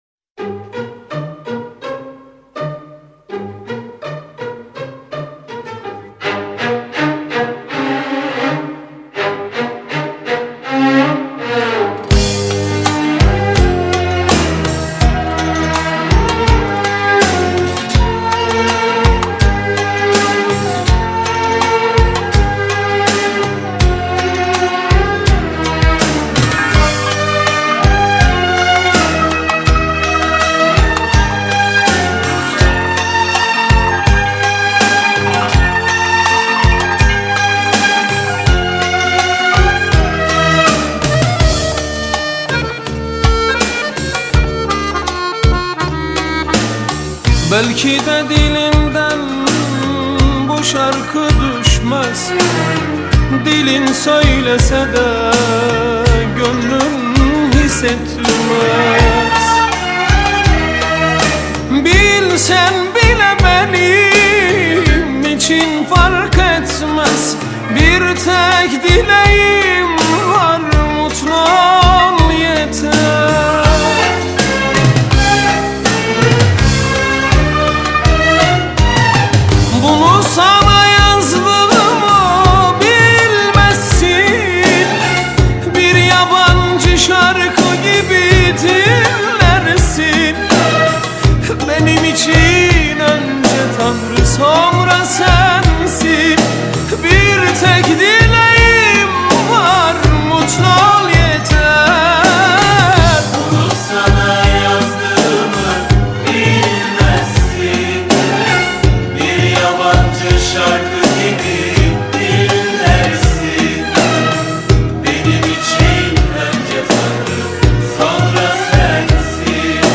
Arabesk, Turkish Folk